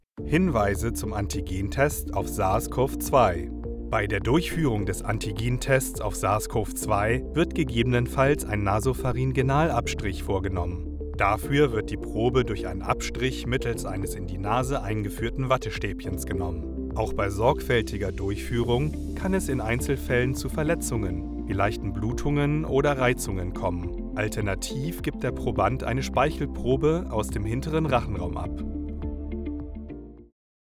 Medical